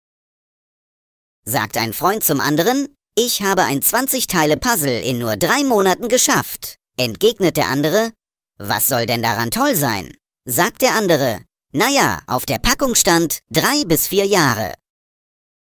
Die Witzschmiede bringt Dir jeden Tag einen frischen Witz als Audio-Podcast. Vorgetragen von unseren attraktiven SchauspielerInnen.